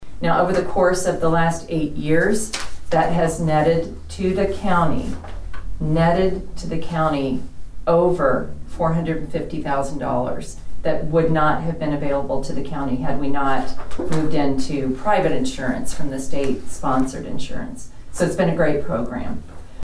The Board of Nowata County Commissioners held a regular meeting on Monday morning at the Nowata County Annex.